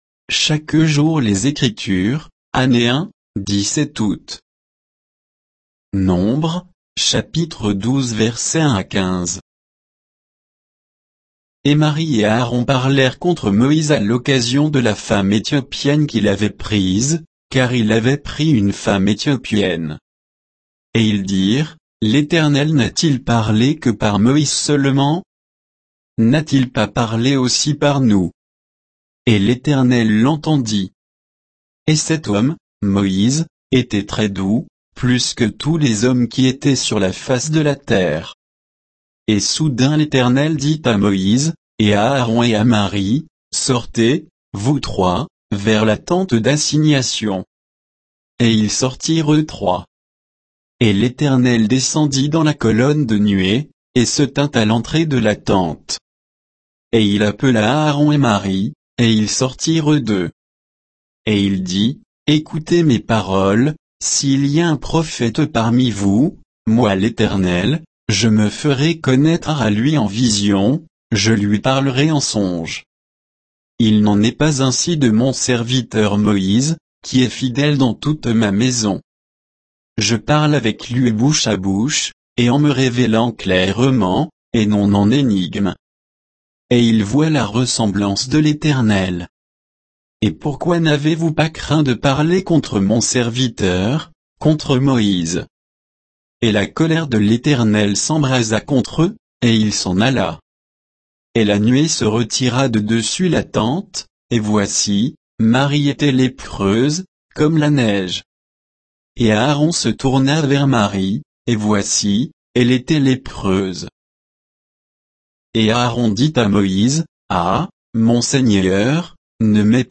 Méditation quoditienne de Chaque jour les Écritures sur Nombres 12